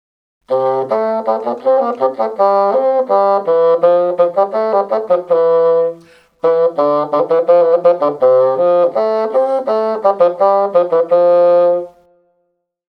groep6_les1-5-2_blaasinstrumenten6_fagot
groep6_les1-5-2_blaasinstrumenten6_fagot.mp3